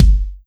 • Kick Drum G Key 161.wav
Royality free steel kick drum sample tuned to the G note. Loudest frequency: 288Hz
kick-drum-g-key-161-DoE.wav